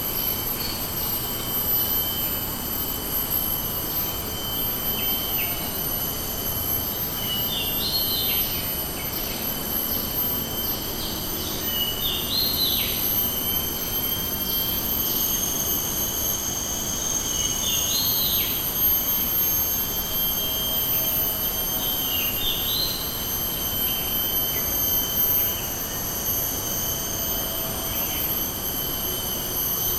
Bukit_Dinding_rainforest_jungle_01
Category 🌿 Nature
ambient bird field-recording jungle kualalumpur malaysia rainforest stereo sound effect free sound royalty free Nature